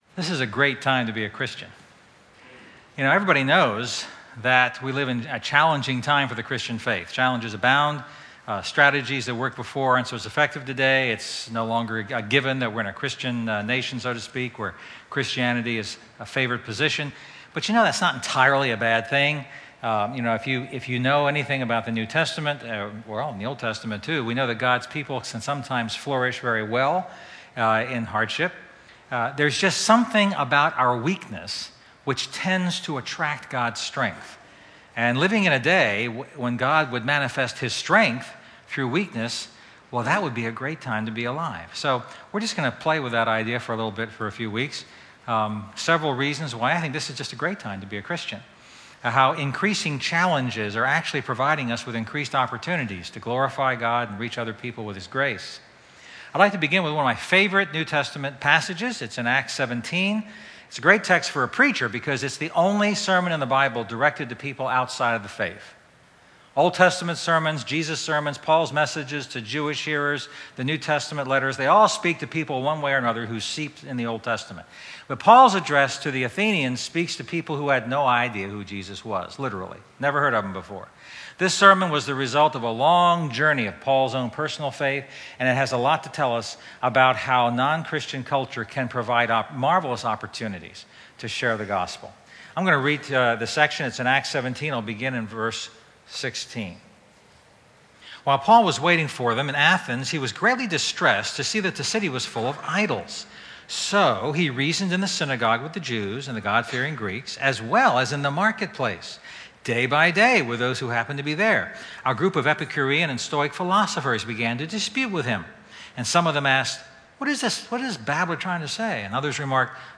Sermons – GrowthGround